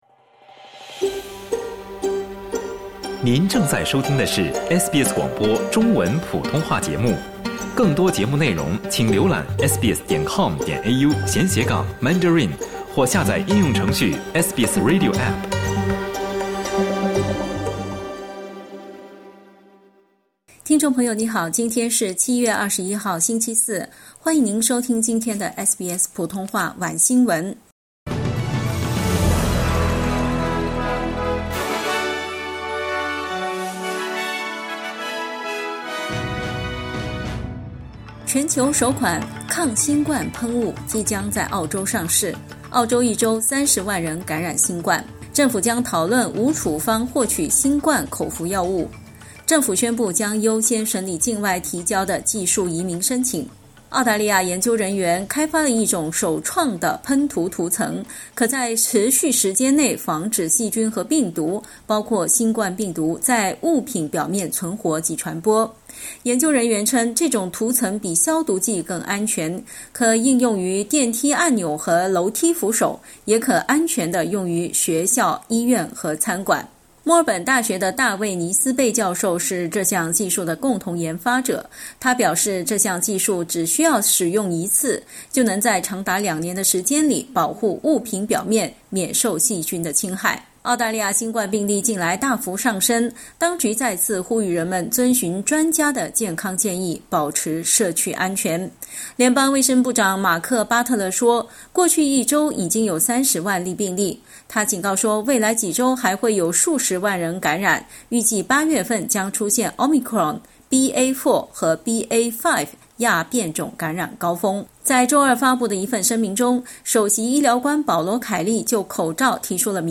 SBS 晚新闻 (21/07/2022)
SBS Mandarin evening news Source: Getty Images